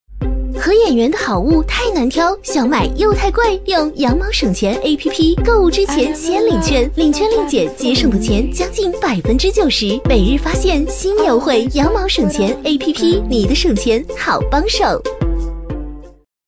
【女50号广告】欢快-电台广告-羊毛APP
【女50号广告】欢快-电台广告-羊毛APP.mp3